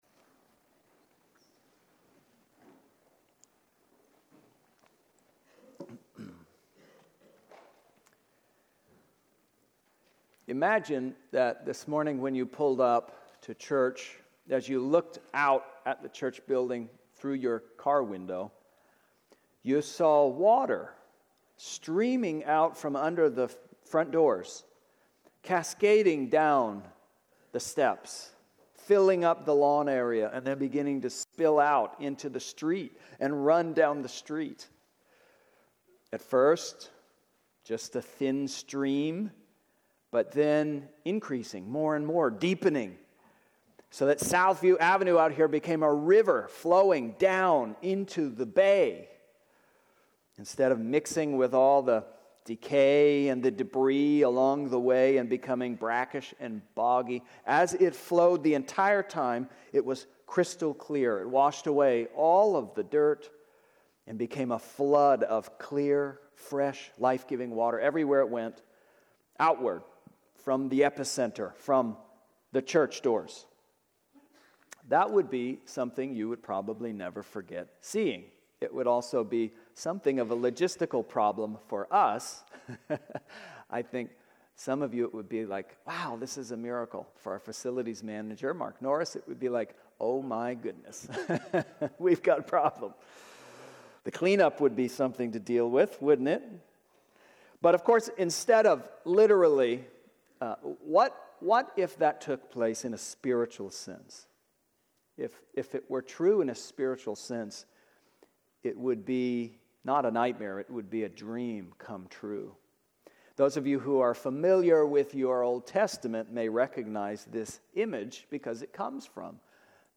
Sermons | Trinity Presbyterian Church